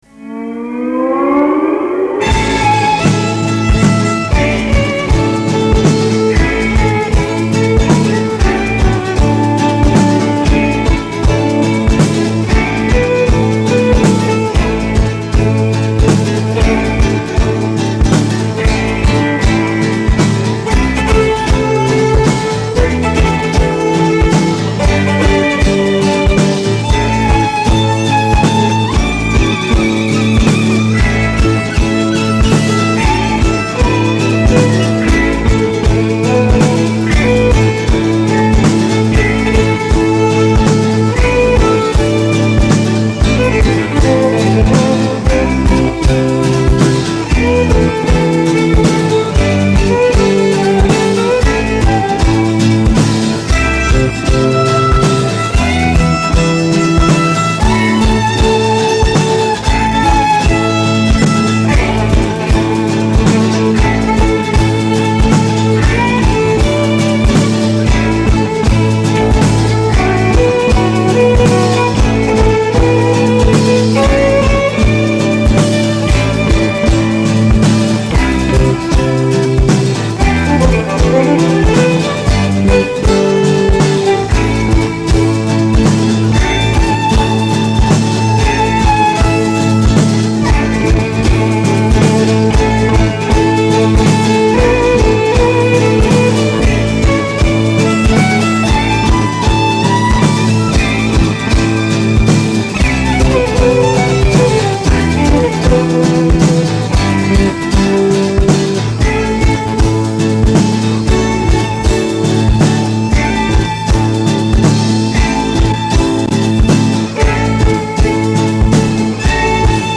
The following pieces were recorded June 26th 1981 at Cosmic City Studio, Lohhof (near Munich), Germany, during the sessions which were supposed to be our first record, but this never happened.
Guitar
Violin
Bass
Vocals